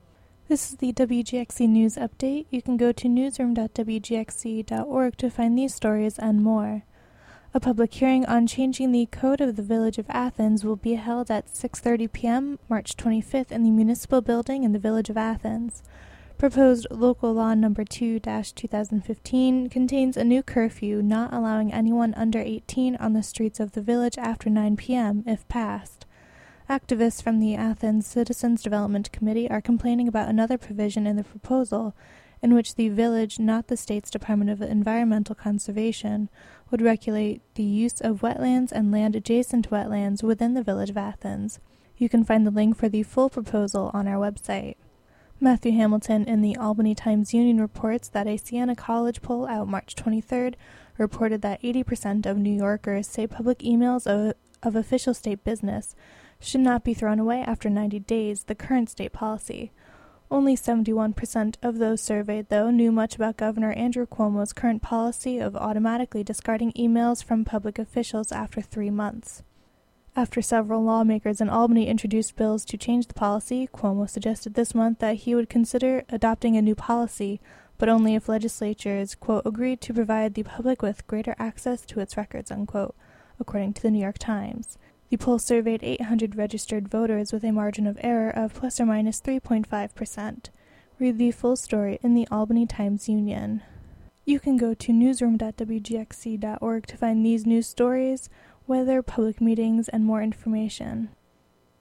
Produced by staff and volunteers of WGXC.